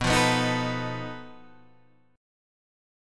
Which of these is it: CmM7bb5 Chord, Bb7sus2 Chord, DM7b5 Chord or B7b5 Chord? B7b5 Chord